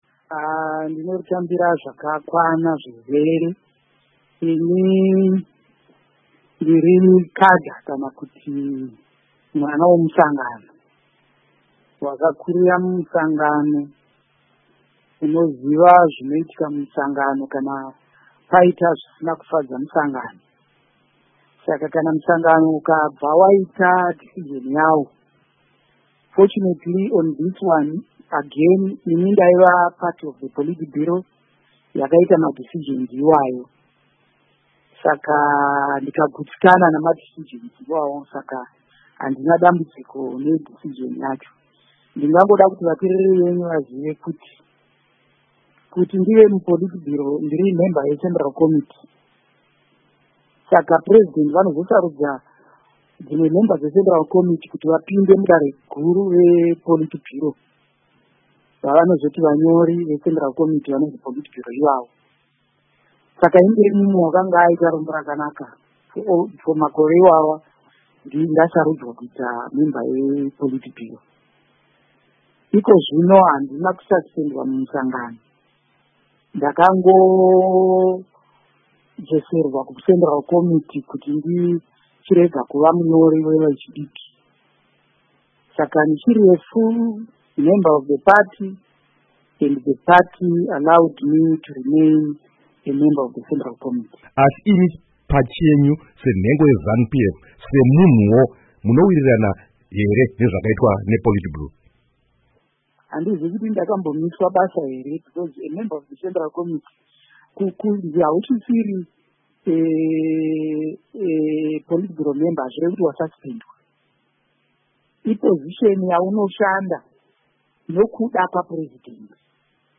Hurukuro naVaPupurai Togarepi